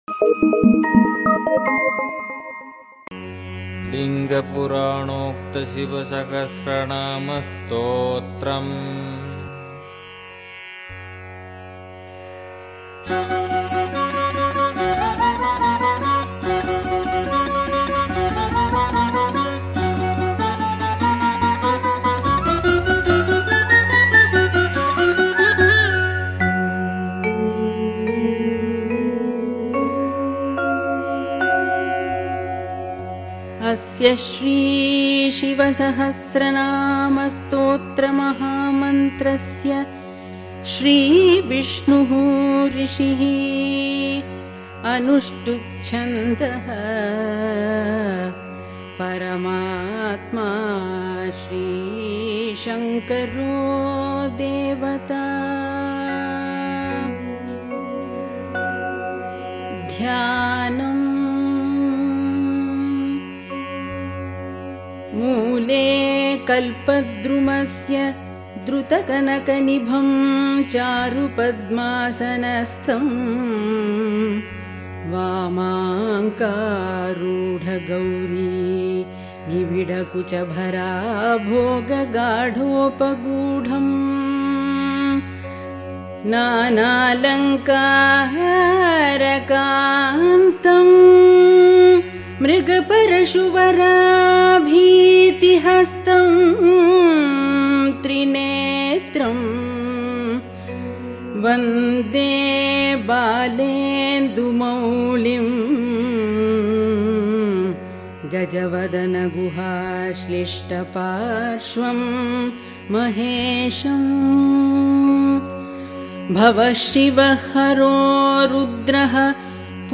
Sri Mahaperiyava has advised chanting of this stotra for universal harmony. I found the below audio quite clear and easy to follow [thanks to Geethanjali -Music and Chants].
Shiva-Sahasranama-Stotram-Lingapurana-_-Removes-all-Negative-Energy-that-Obstructs-Ones-Success-HR4HYY1A0TI.mp3